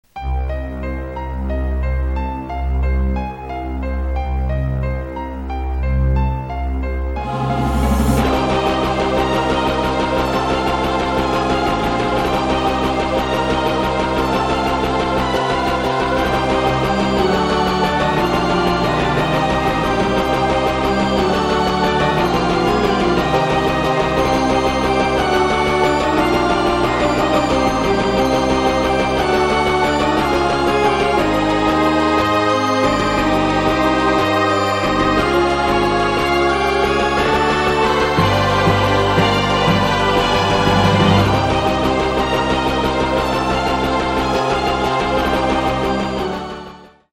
お遊びなので、パート数も最低限で、音色やボリュームも適当です。
（ヘッドホンで音量調整しているのでスピーカーで聴くと低音が小さいはずです・・・）
029 　ゲーム風〜○ラキュラ風？〜（Cm） 07/04/21